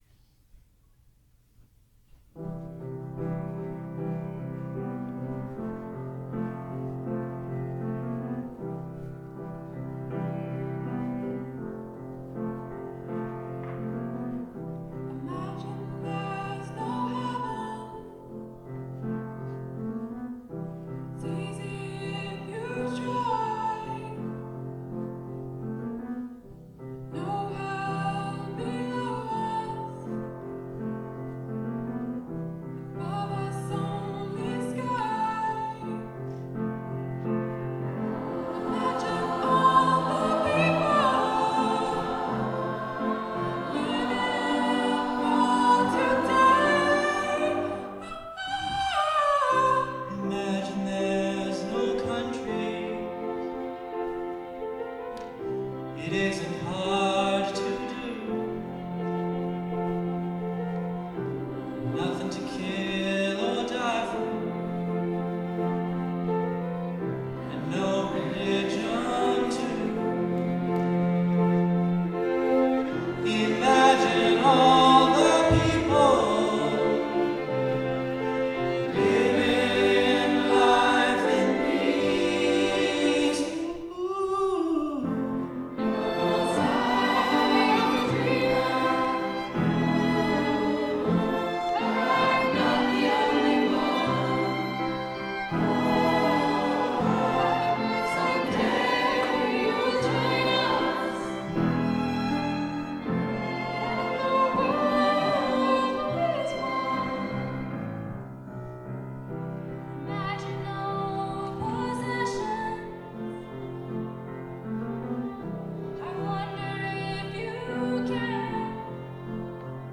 Chamber, Choral & Orchestral Music
2:00 PM on August 13, 2017, St. Mary Magdalene
Chorus